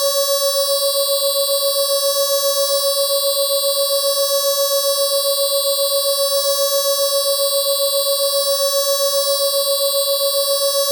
Additive Synthesis
I have experimented lately with setting it up as a dual chain where the modulation for each chain has a global offset to get some nice stereo modulation effects.